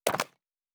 pgs/Assets/Audio/Fantasy Interface Sounds/UI Tight 09.wav at master
UI Tight 09.wav